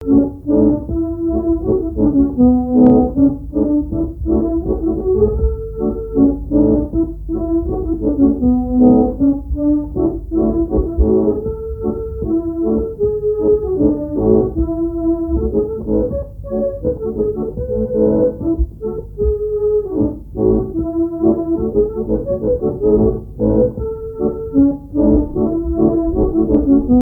danse : scottich trois pas
Répertoire à l'accordéon diatonique
Pièce musicale inédite